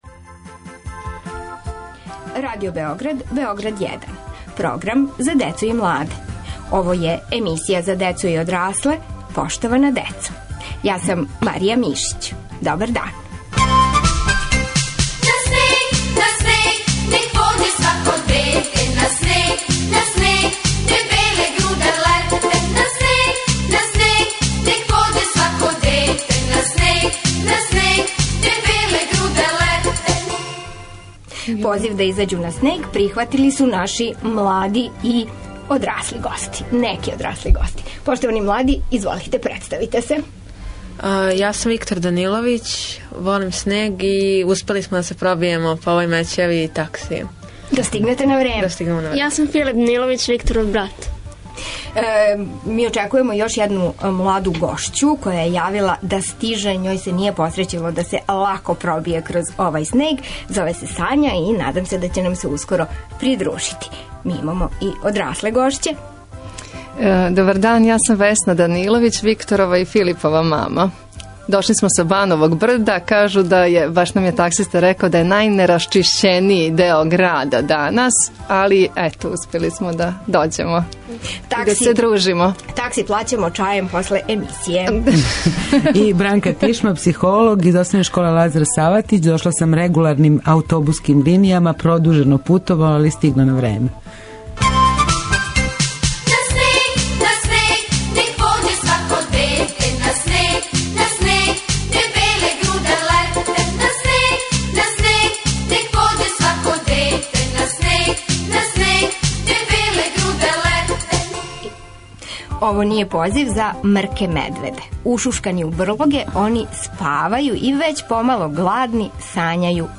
Гости емисије су заинтересовани сањари, млади и одрасли.